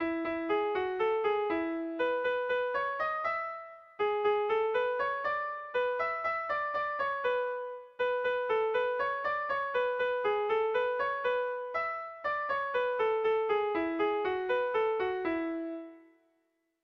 Irrizkoa
Zortziko txikia (hg) / Lau puntuko txikia (ip)
A-B-C-D